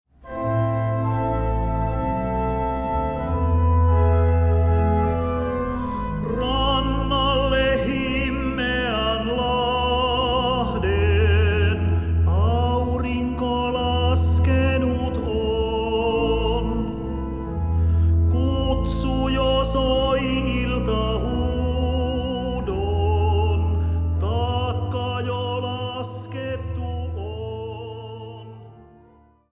Urkuäänitykset: Karjasillan kirkko
Pianoäänitykset: Thulinbergin sali